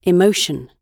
Emotion [ɪˈməʊʃ(ə)n]
emotion__gb_1.mp3